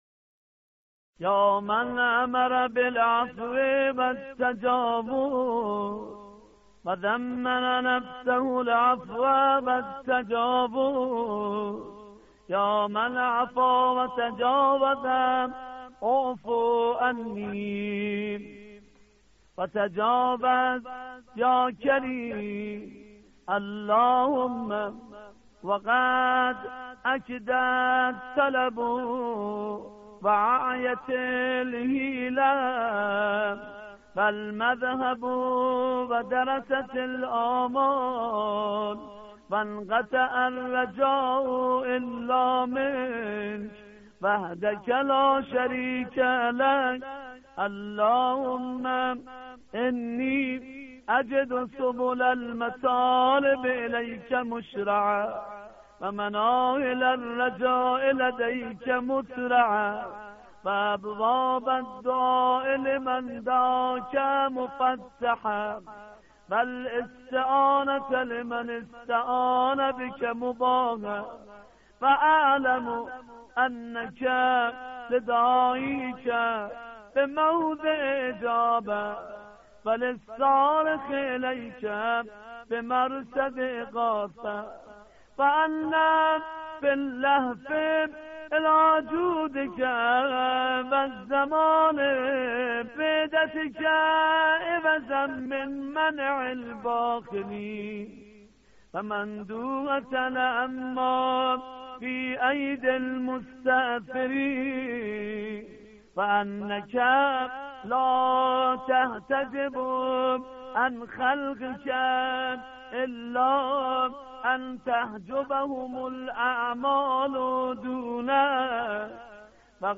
دعای روز 27 رجب